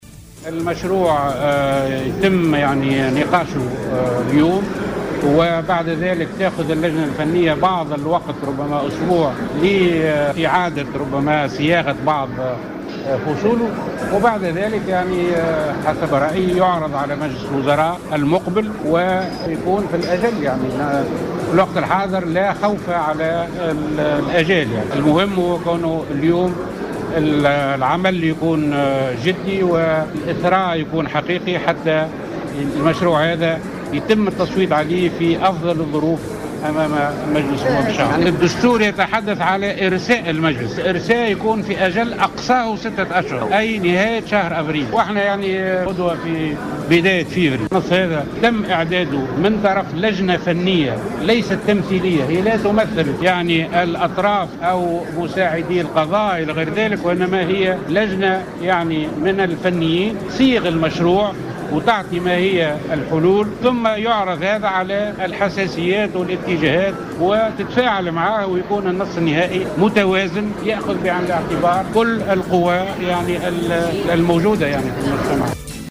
وأضاف الوزير خلال يوم إعلامي نظمته الوزارة حول"مشروع القانون الأساسي المتعلق بالمجلس الأعلى للقضاء" أنه من المنتظر أن يعرض مشروع القانون على مجلس الوزراء المقبل.